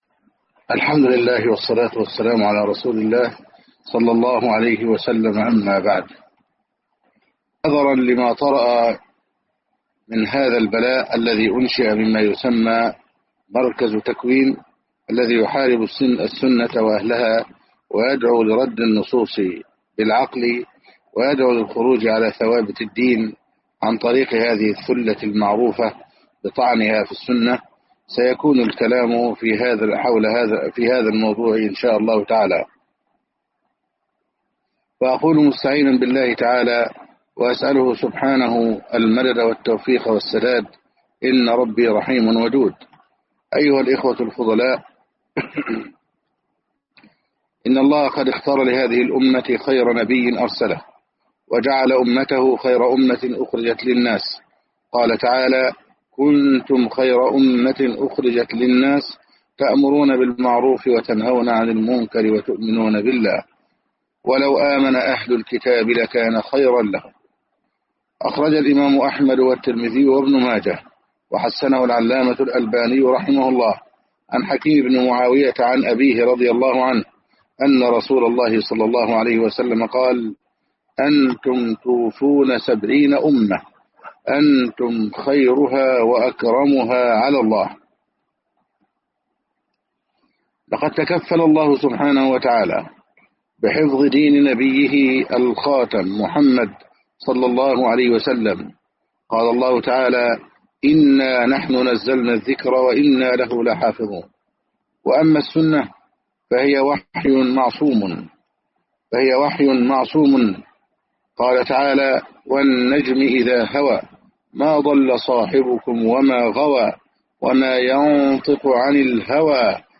محاضرات وكلمات